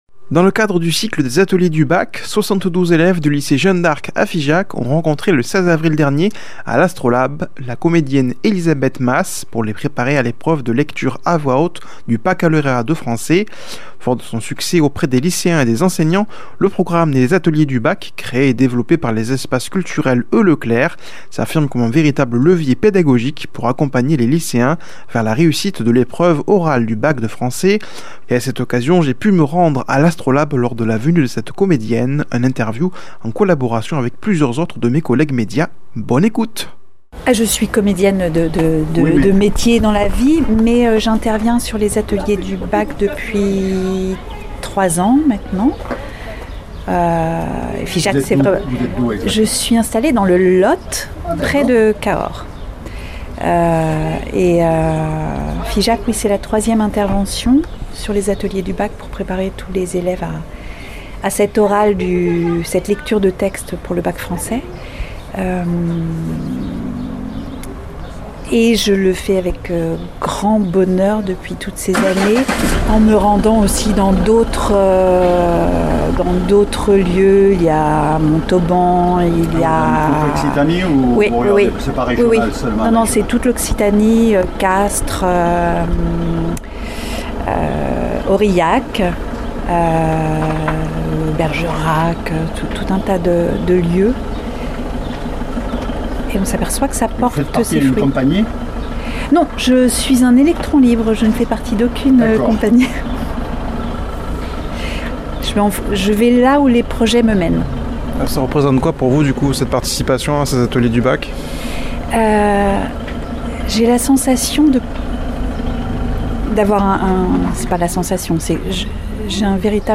Un itw réalisé en collaboration avec des collègues médias
Présentateur